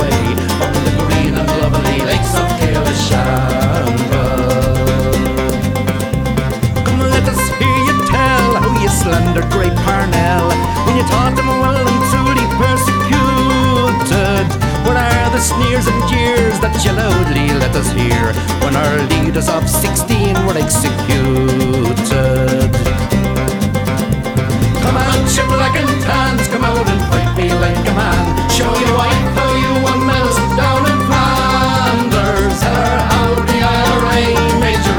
Singer Songwriter
Celtic